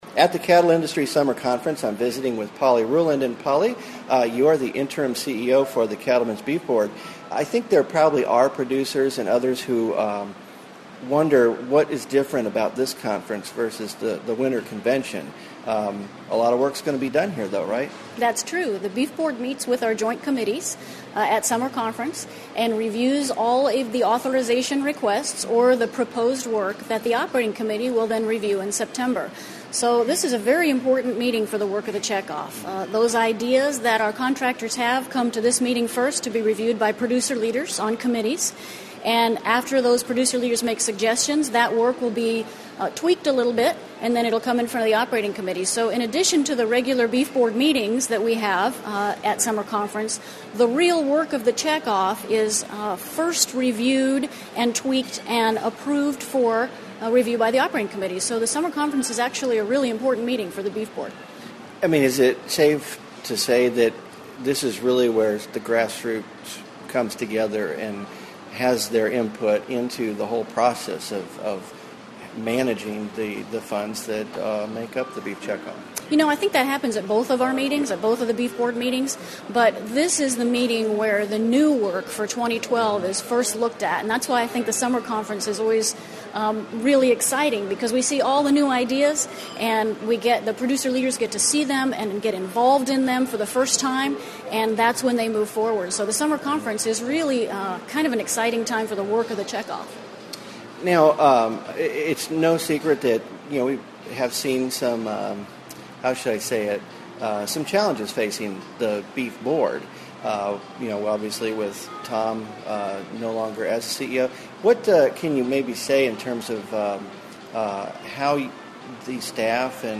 Interview
2011 Cattle Industry Summer Conference Photo Album